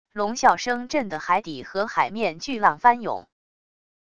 龙啸声震的海底和海面巨浪翻涌wav音频